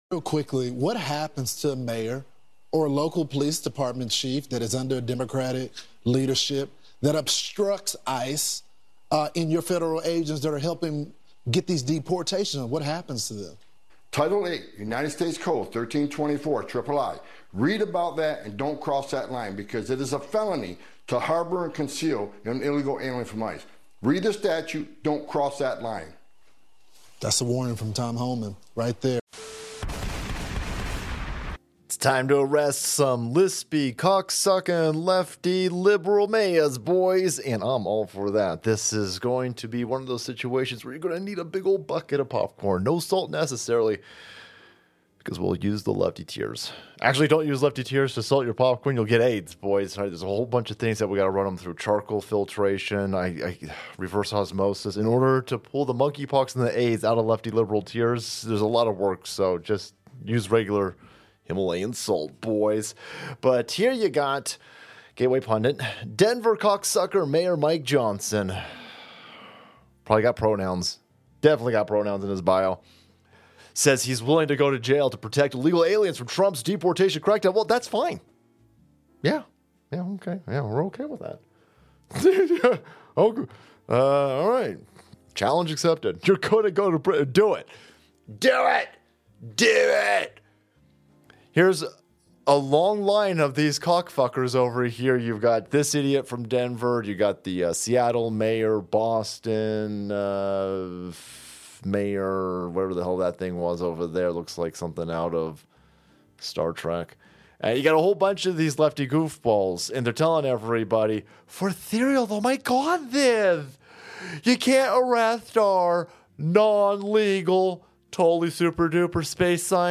Disclaimer: These are the opinions and ramblings of a foul-mouthed lunatic.